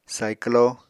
[saiklo] v. bicycle